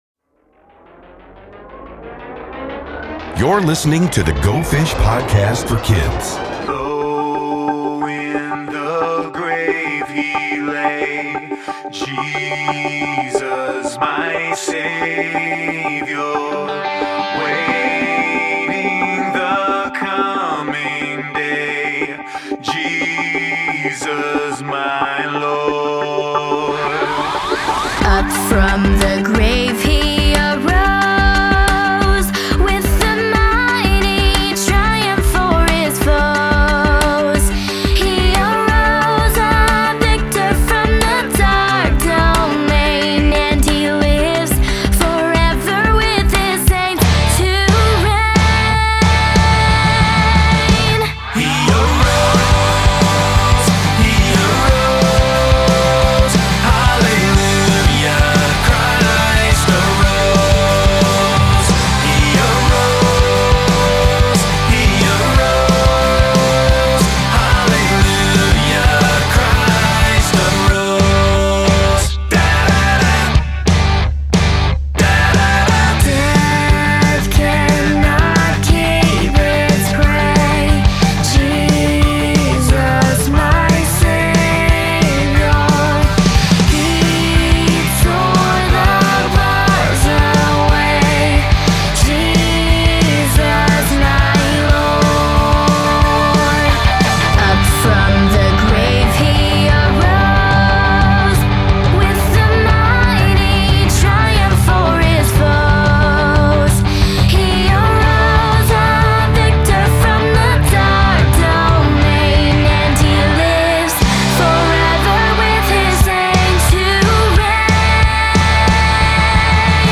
Go Fish uses music and Scripture to celebrate Easter!